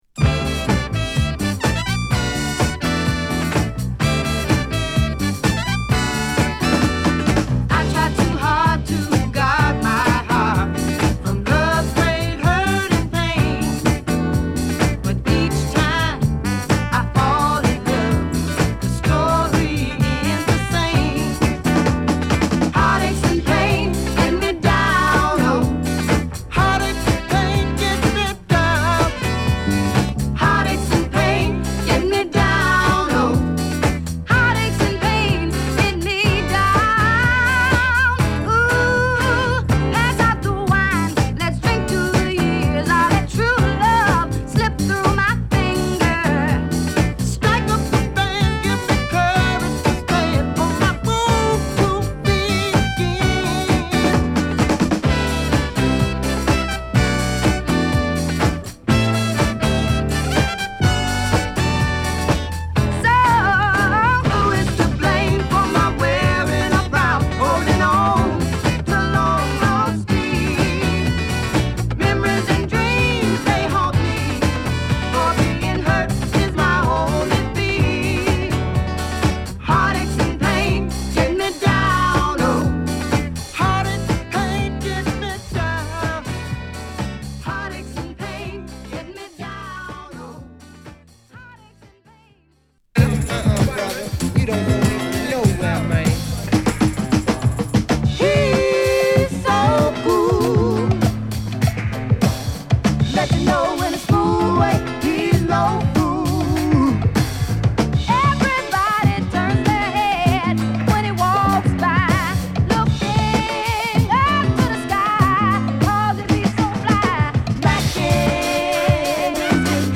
伸び伸びと展開する男女のツイン・リードが心地よい爽快なモダン・ソウル・ダンサー
カッチリ黒いスリリングなゲットー・ファンク